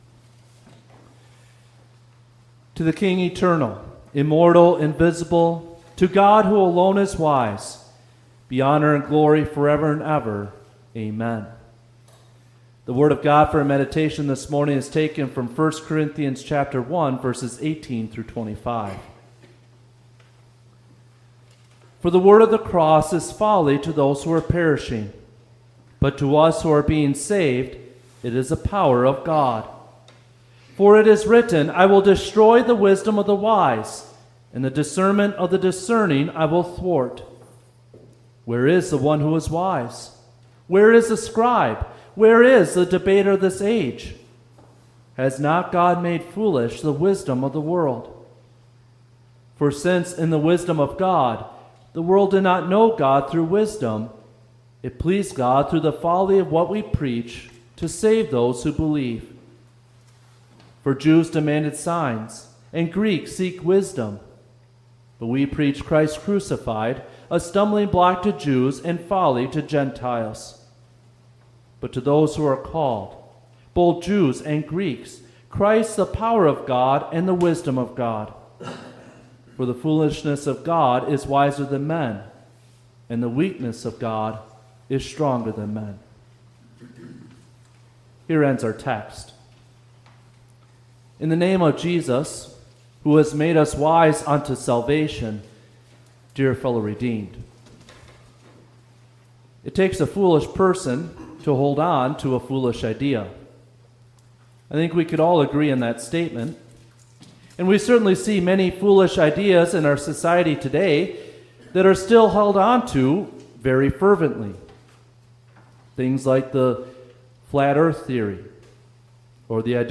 Fifth-Sunday-after-Trinity-Service.mp3